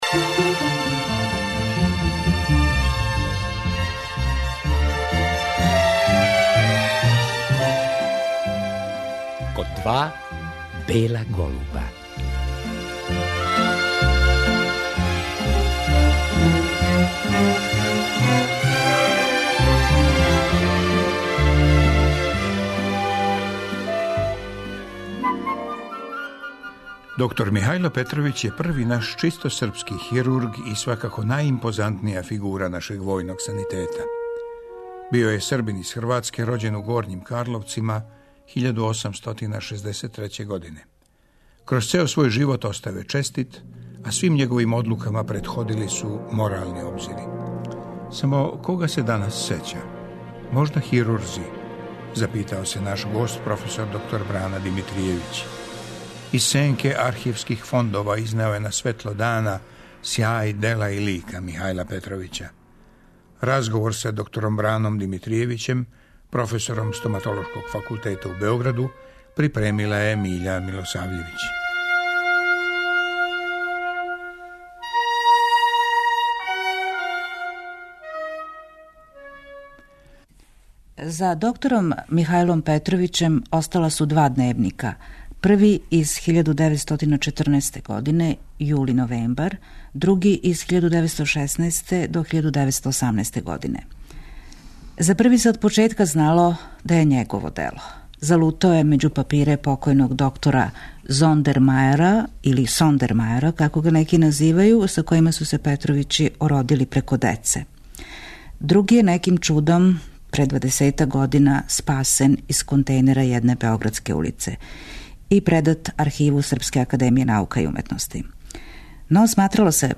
Ми ћемо поново чути тај разговор, а поводом вести да је у кругу болнице ВМА подигнута биста др Михаилу Петровићу.